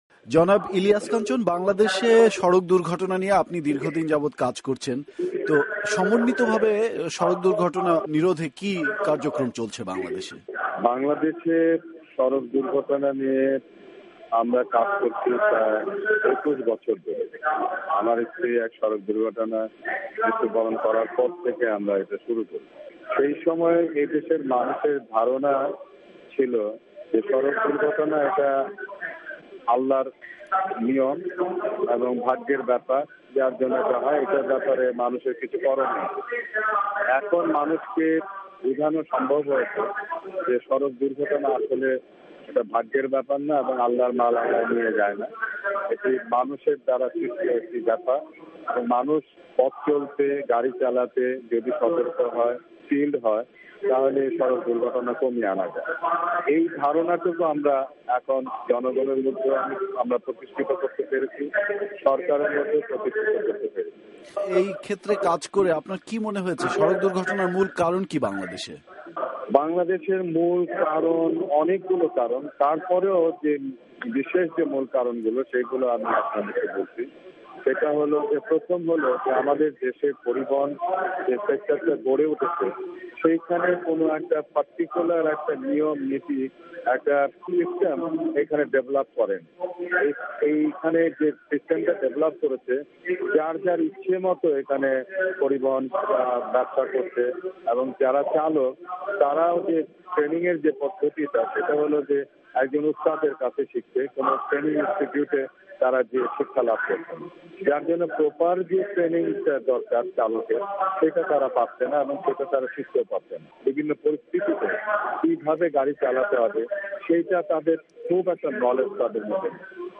সড়ক দুর্ঘটনা রোধে বাংলাদেশে কি করা দরকার সে বিষয়ে কথা বললেন নিরাপদ সড়ক চাই আন্দোলনের প্রতিষ্ঠাতা ইলিয়াস কাঞ্চন। তাঁর সংগঠনের কার্যক্রম ব্যাখ্যার পর তিনি দুর্ঘটনা রোধে করণীয় কিছু পরামর্শ দেন। ওয়াশিংটন ষ্টুডিও থেকে তার সঙ্গে কথা বলেন